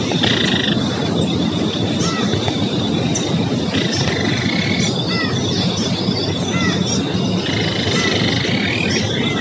Marianinha-amarela (Capsiempis flaveola)
Nome em Inglês: Yellow Tyrannulet
Localidade ou área protegida: Parque Nacional Iguazú
Condição: Selvagem
Certeza: Gravado Vocal